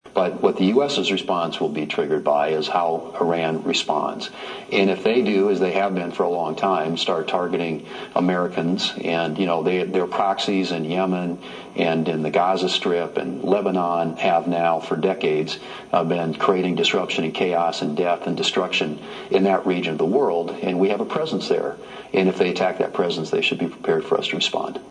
WASHINGTON, D.C.(HubCityRadio)- Fox News Sunday’s host Shannon Breem did a pre-recorded interview with U.S. Senate Majority Leader John Thune which aired on Sunday.